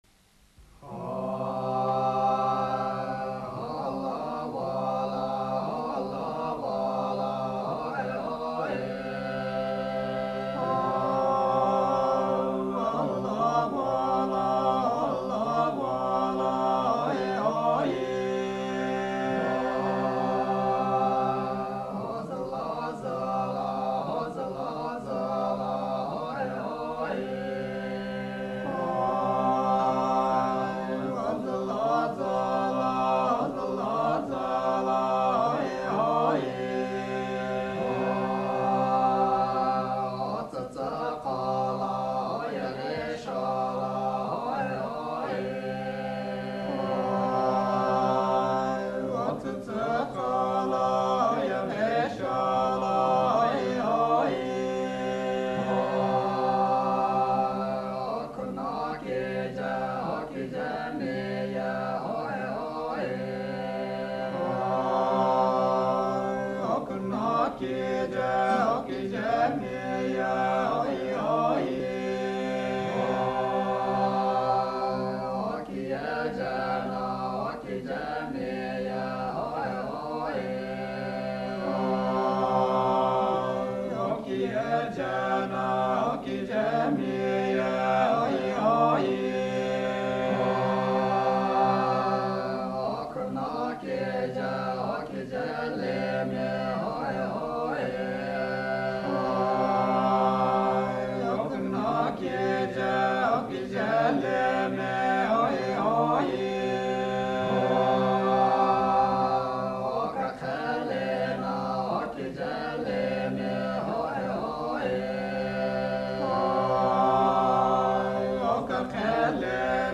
in record sound recording - "Inde-Nagaland : Chant des tribus Sema et Zeliang"